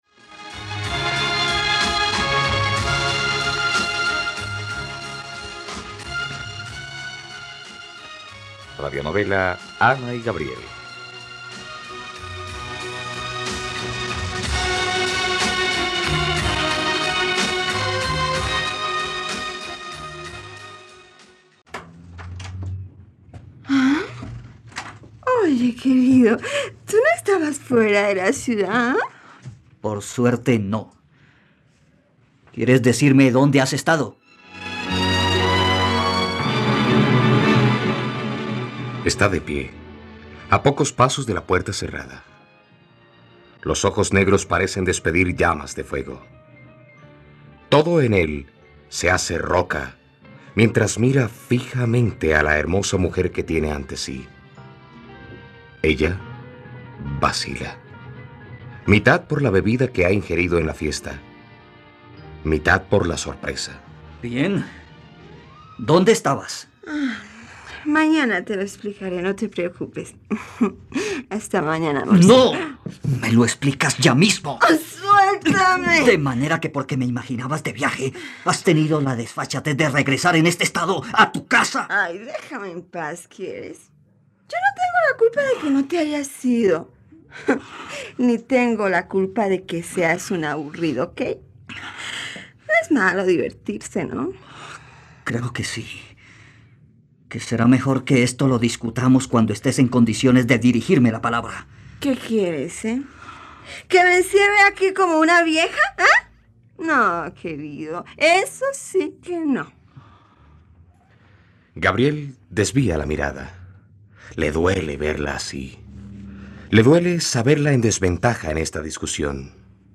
..Radionovela. Escucha ahora el cuarto capítulo de la historia de amor de Ana y Gabriel en la plataforma de streaming de los colombianos: RTVCPlay.